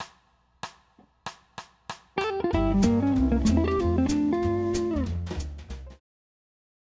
bebop lines